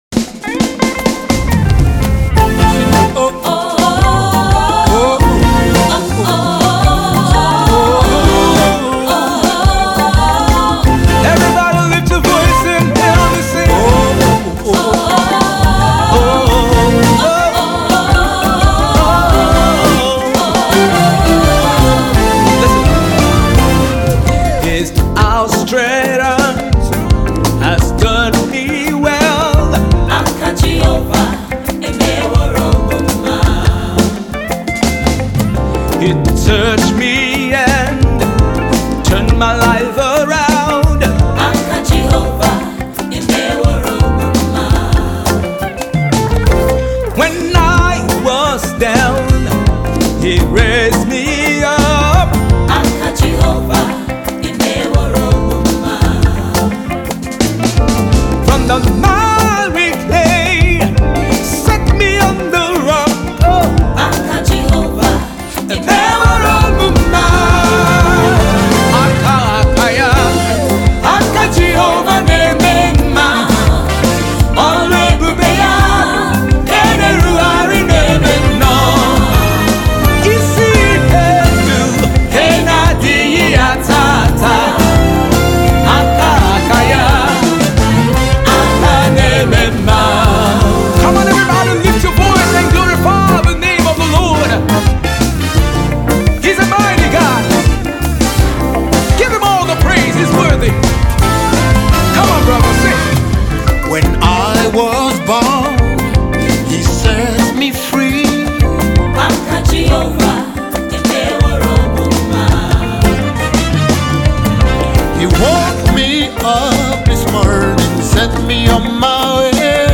Energetic and Vibrant gospel music minister